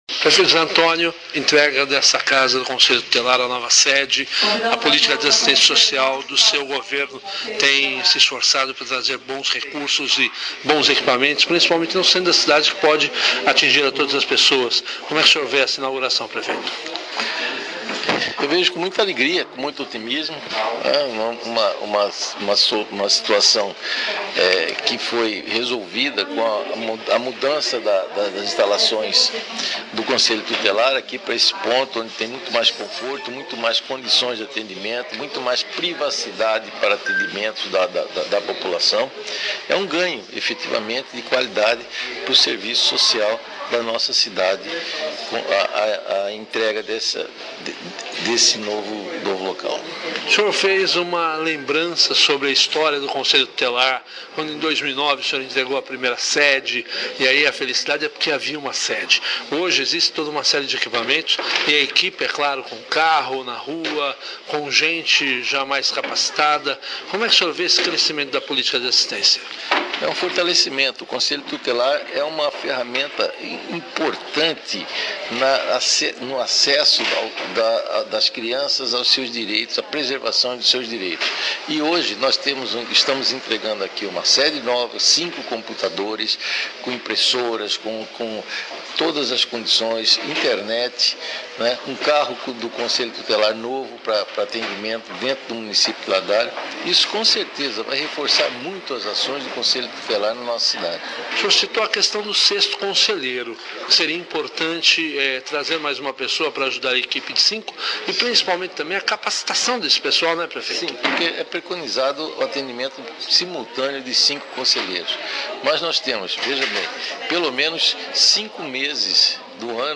Entrevistas
PREFEITO JOSÉ ANTONIO
ZE-ANTONIO-PREFEITO.mp3